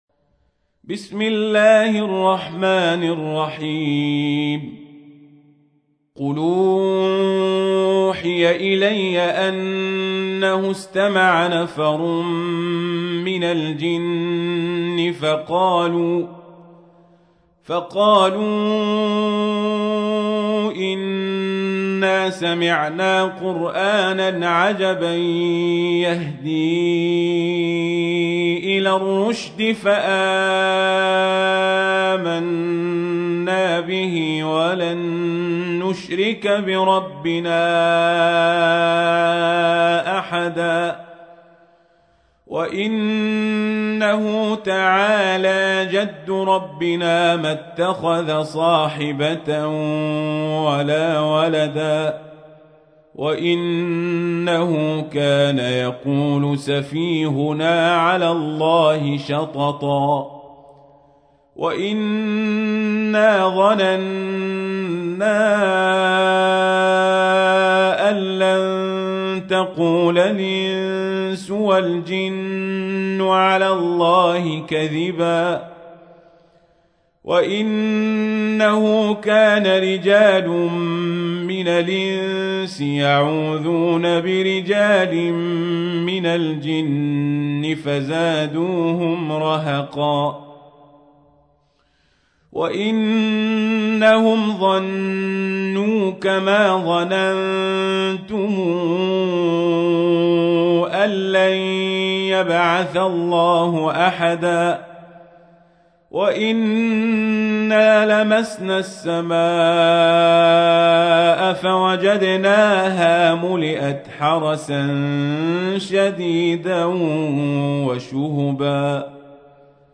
تحميل : 72. سورة الجن / القارئ القزابري / القرآن الكريم / موقع يا حسين